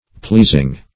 Pleasing \Pleas"ing\, n.